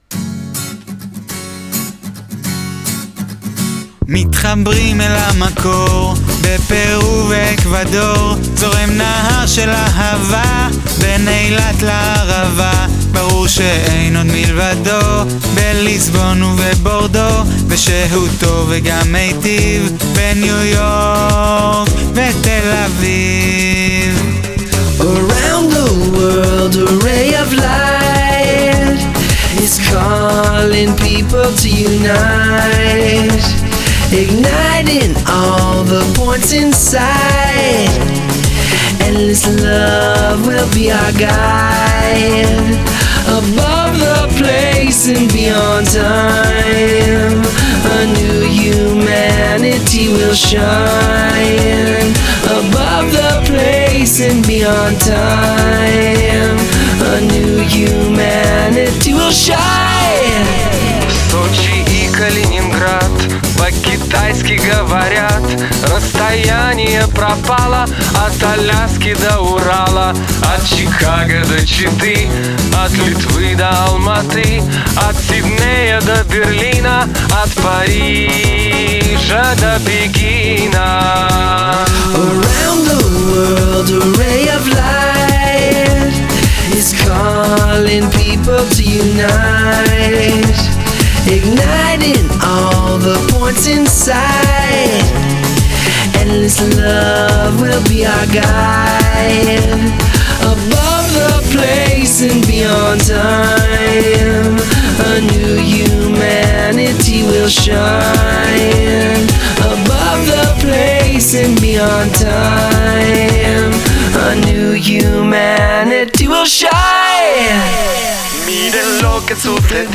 In mehreren Sprachen wird da gesungen…